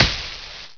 hay_bale.WAV